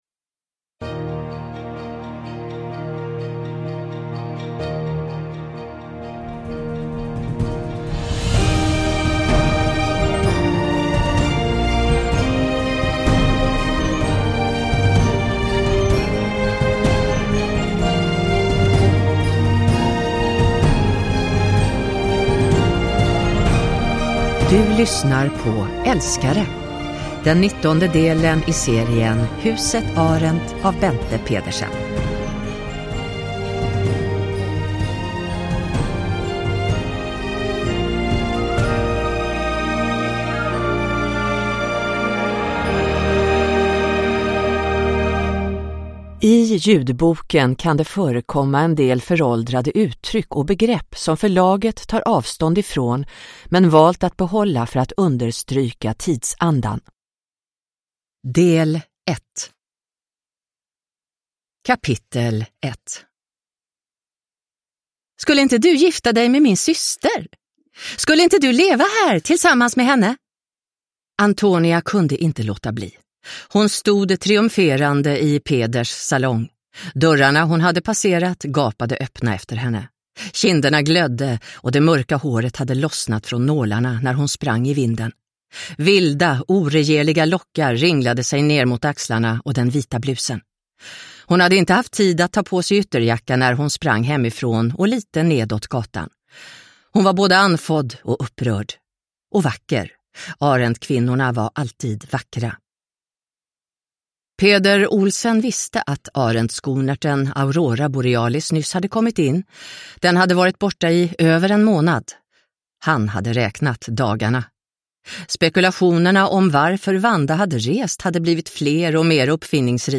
Älskare – Ljudbok – Laddas ner